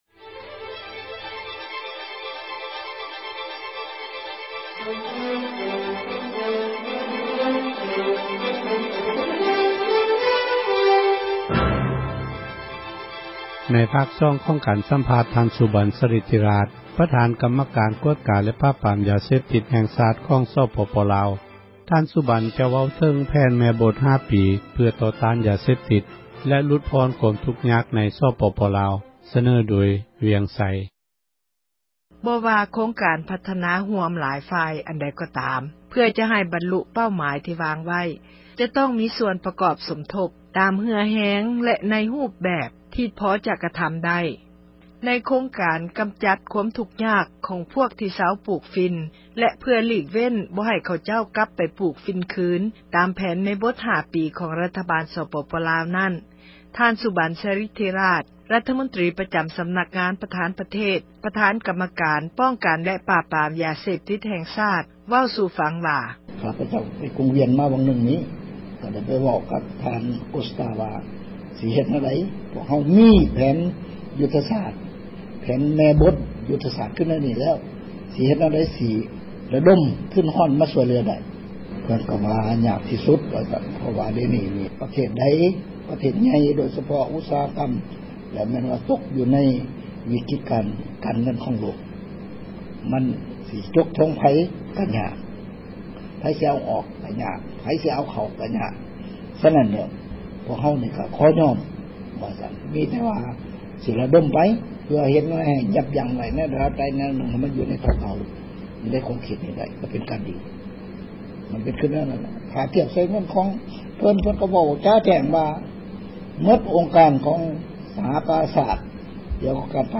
ສັມພາດ ທ່ານສຸບັນ ສາຣິດທິຣາຊ (ຕໍ່)